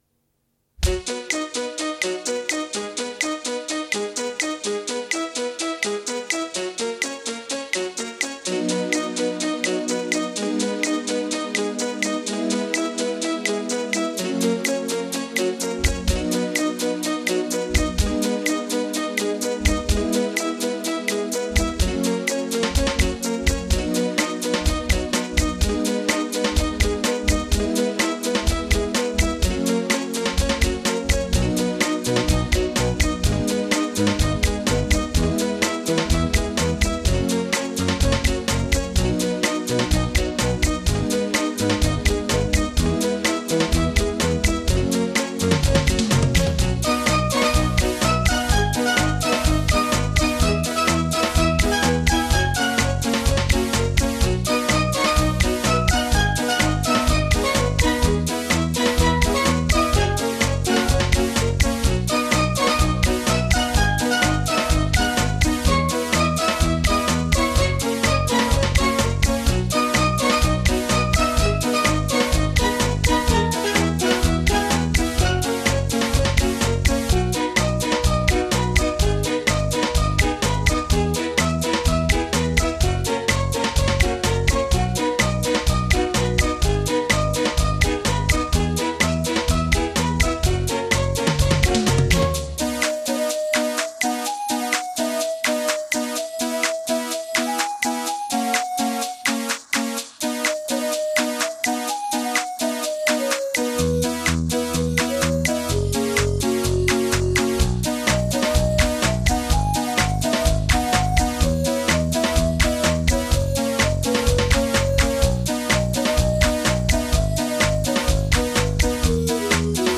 Darf man alles nicht so ernst nehmen, ist meist eh alles mit ganz wenig Equipment und noch weniger Professionalität entstanden.
Schlechte Audioqualität, weil damals nur auf Kassette aufgenommen und nie wirklich richtig abgemischt.